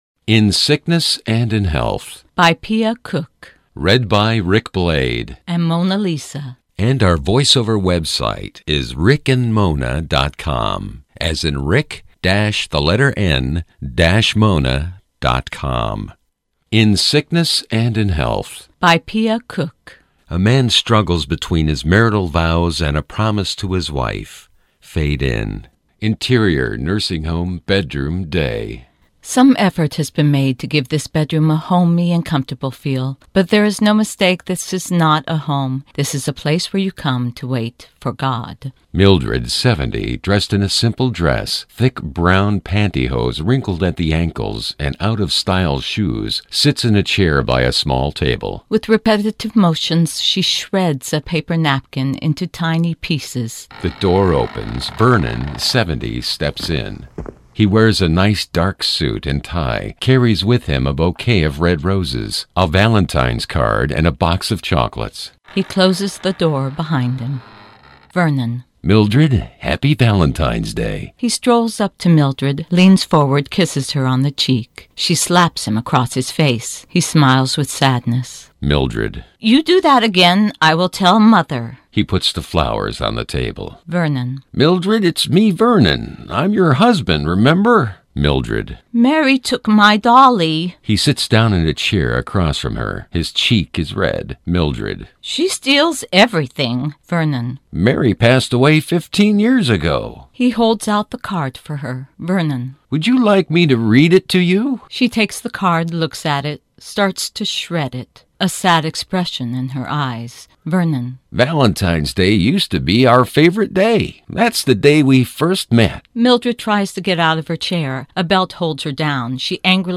Read by Macblade Productions : She and He Voice Overs
Short, Drama